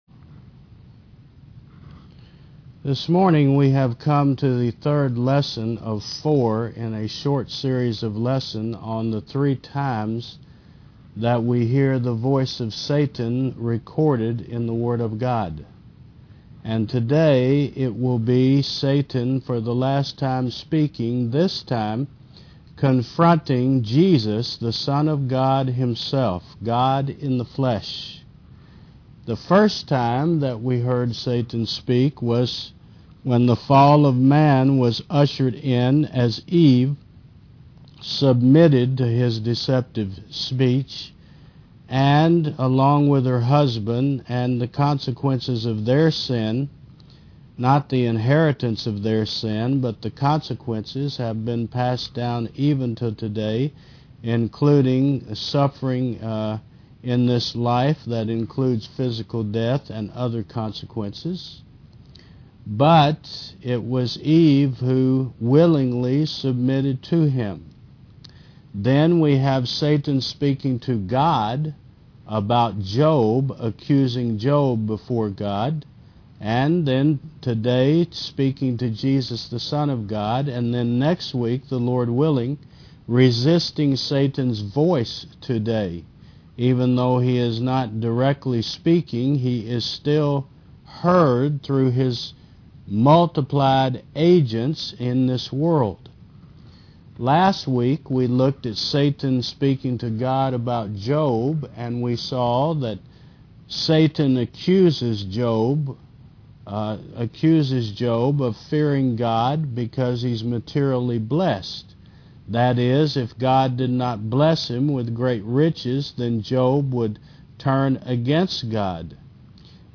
Service Type: Sun. 11 AM